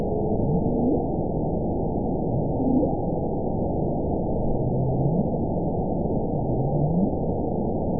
event 919471 date 01/03/24 time 19:21:49 GMT (1 year, 4 months ago) score 7.67 location TSS-AB08 detected by nrw target species NRW annotations +NRW Spectrogram: Frequency (kHz) vs. Time (s) audio not available .wav